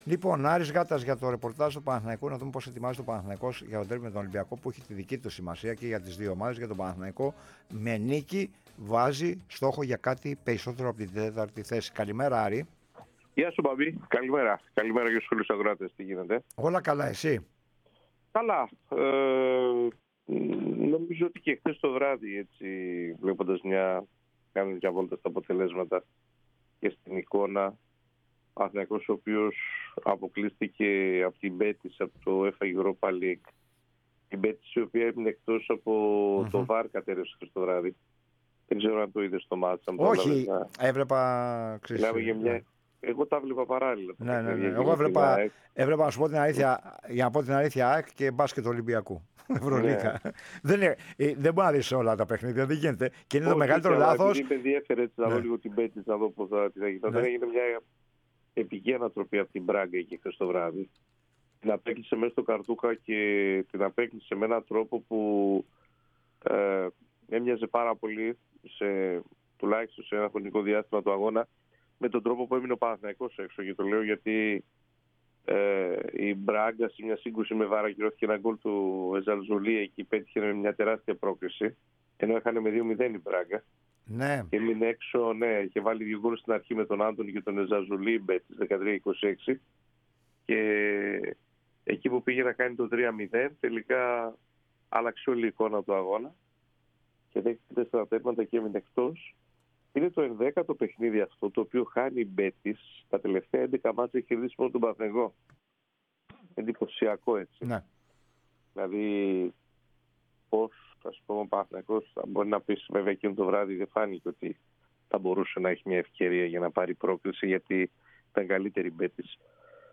Super LeagueΠΑΝΑΘΗΝΑΙΚΟΣ